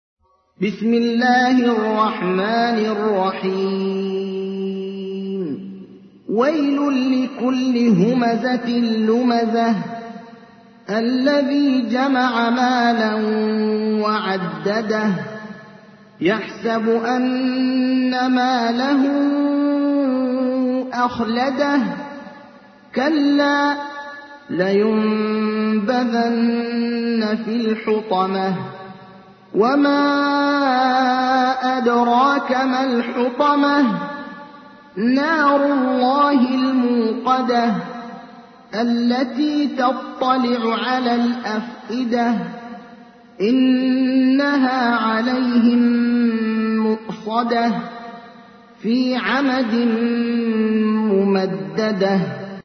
تحميل : 104. سورة الهمزة / القارئ ابراهيم الأخضر / القرآن الكريم / موقع يا حسين